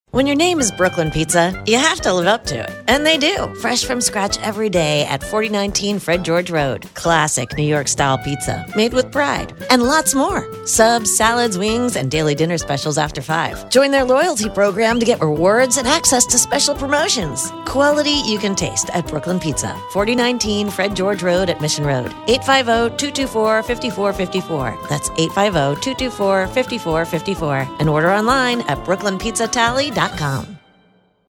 Radio Spot 2